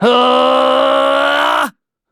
Kibera-Vox_Casting4.wav